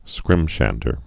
(skrĭmshăndər)